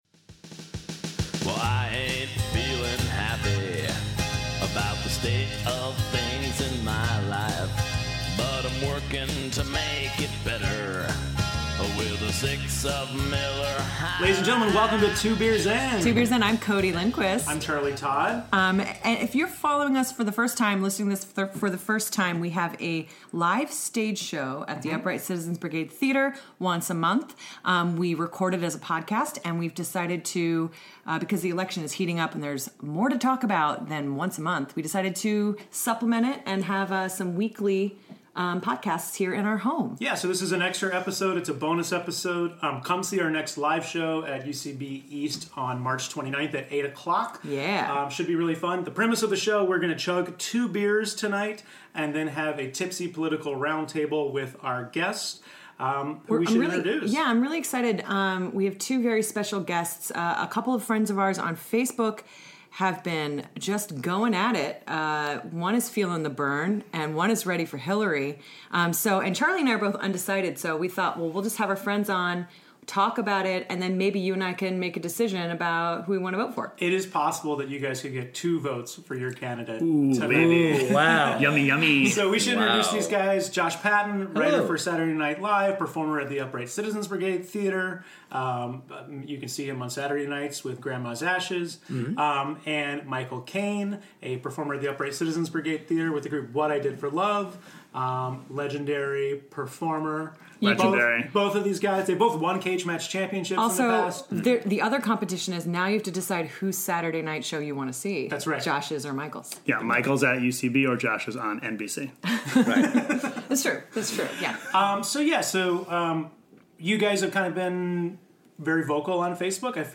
Recorded in our Hell's Kitchen apartment on February 25, 2016.